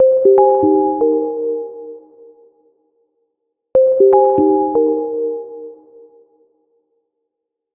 ringing.opus